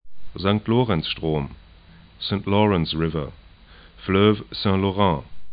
'zaŋkt 'lo:rɛnts 'ʃtro:m